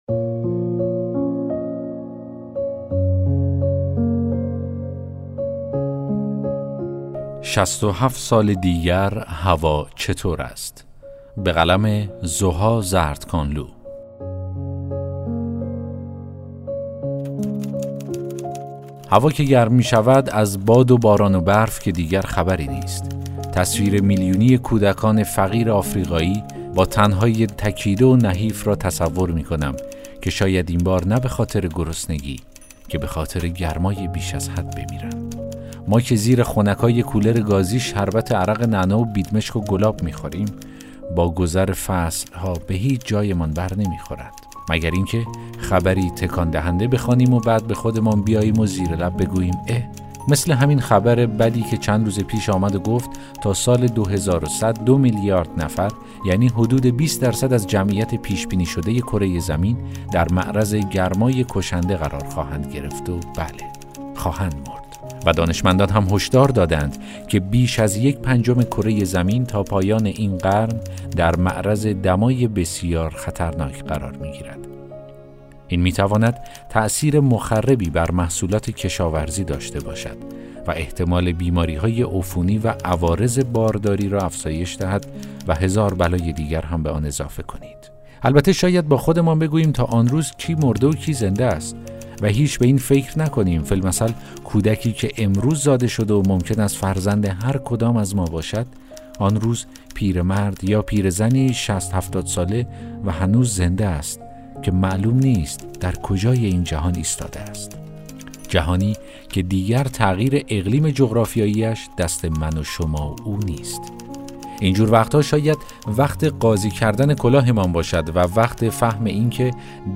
داستان صوتی: ۶۷ سال دیگر هوا چطور است؟